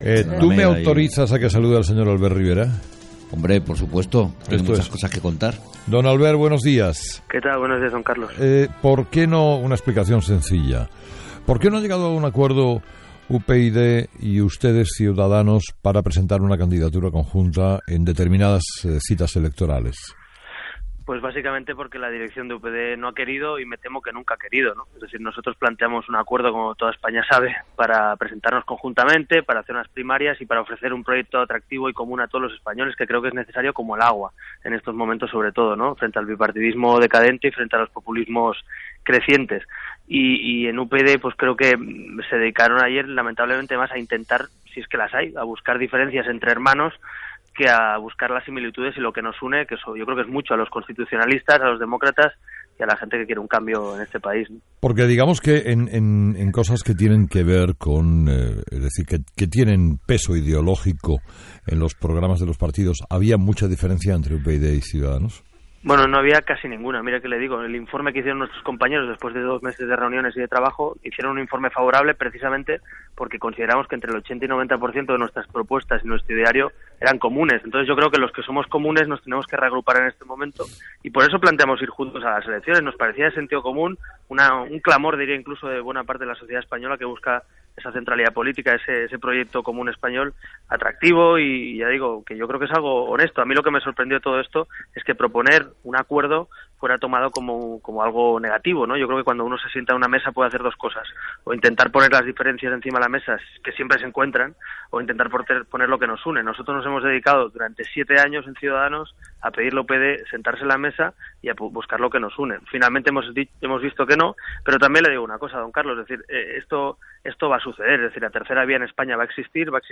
Entrevista a Albert Rivera...
Entrevistado: "Albert Rivera"